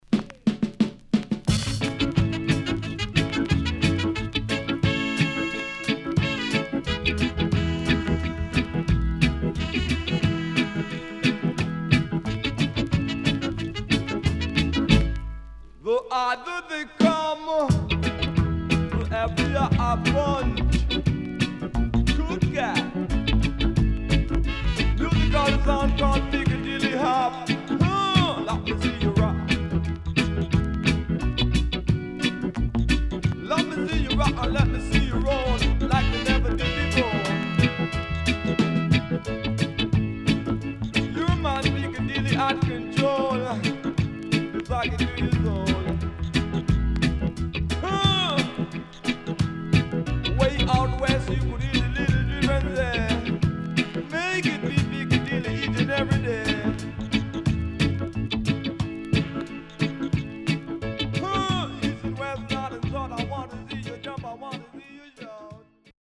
HOME > REGGAE / ROOTS  >  EARLY REGGAE
CONDITION SIDE A:VG(OK)
Good Vocal & Deejay
SIDE A:所々ノイズ入ります。